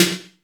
Index of /90_sSampleCDs/Masterbits - Soniq Elements/SPECL FX 9+8/WET SNARES
WET S808  -L 5.wav